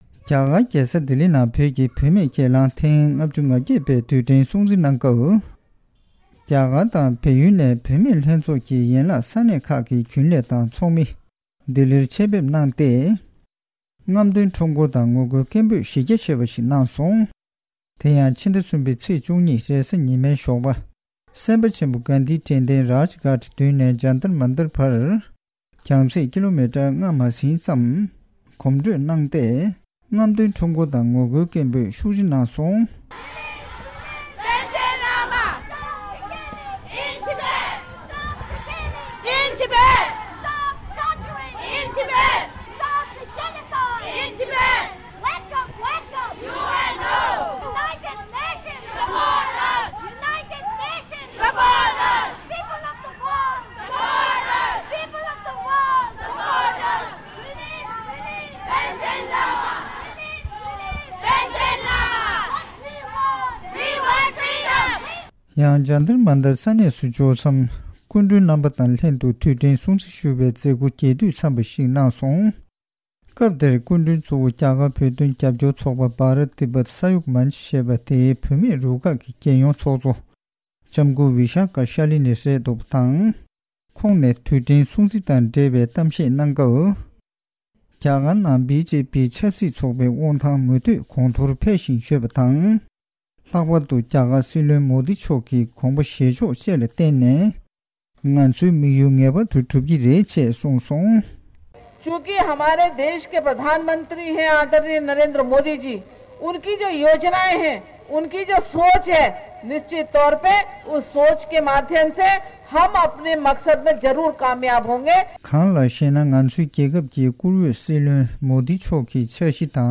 བཞུགས་སྒར་ནས་སྤེལ་བའི་གནས་ཚུལ་ཞིག་གསན་གྱི་རེད།